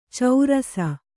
♪ caura s